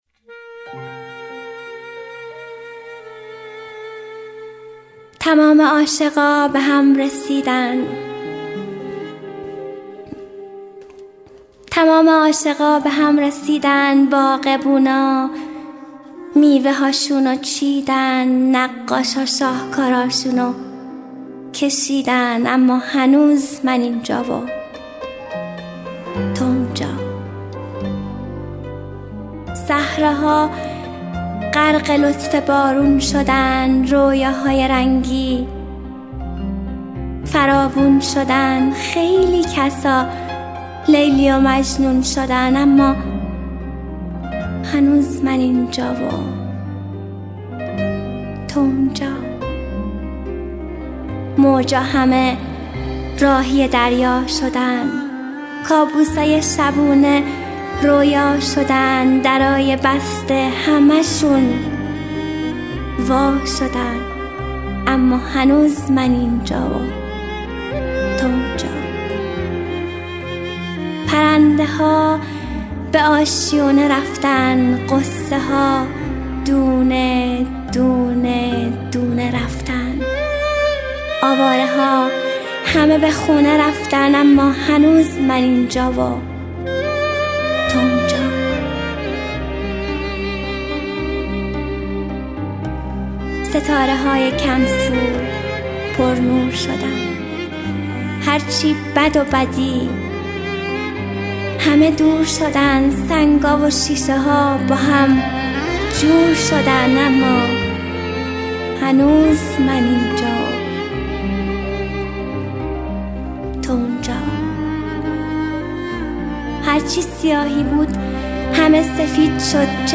دانلود دکلمه من اینجا تو اونجا با صدای مریم حیدرزاده
گوینده :   [مریم حیدرزاده]